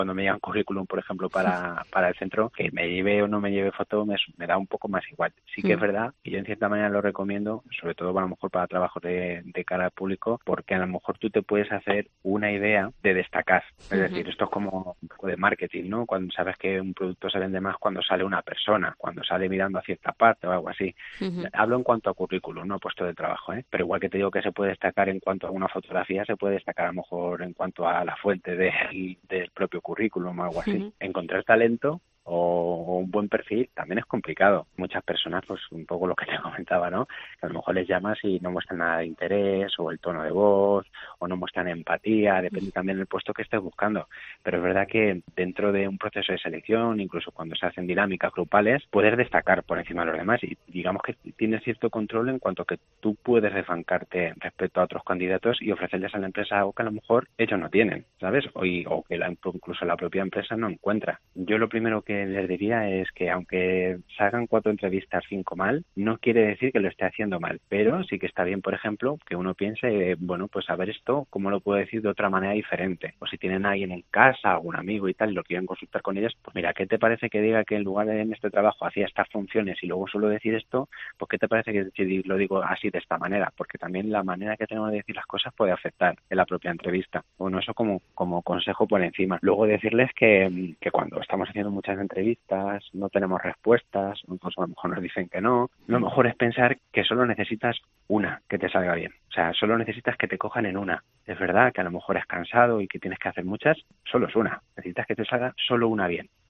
psicólogo experto en RRHH